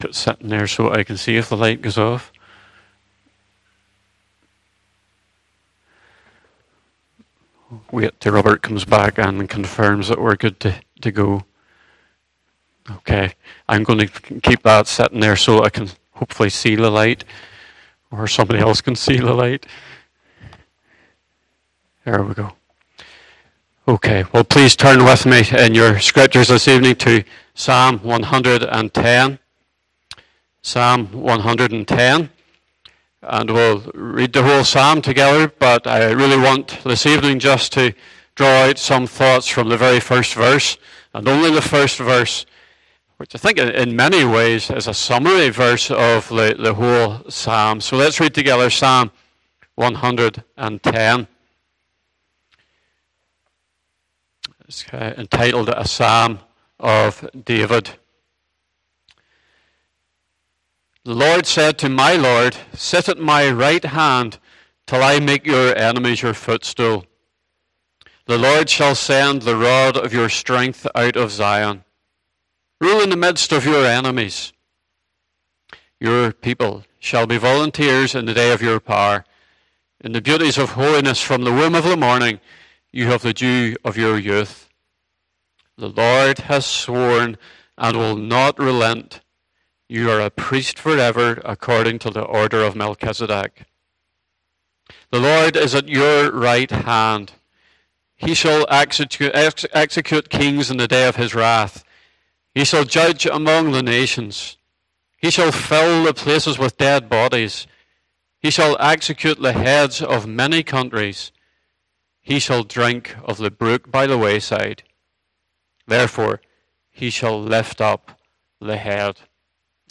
Psalm 110 Service Type: Evening Service Bible Text